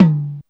• Low Mid Tom Drum One Shot D Key 40.wav
Royality free tom drum one shot tuned to the D note. Loudest frequency: 253Hz
low-mid-tom-drum-one-shot-d-key-40-4vj.wav